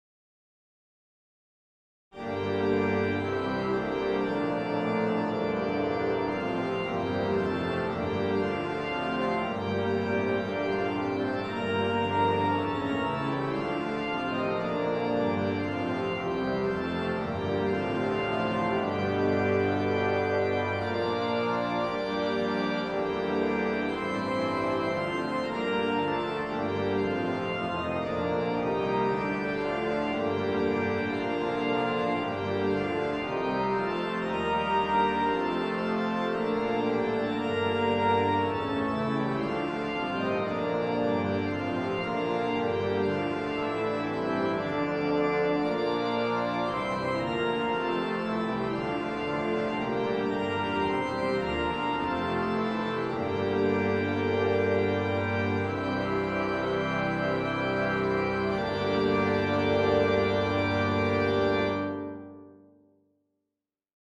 GP-023-Tutti.mp3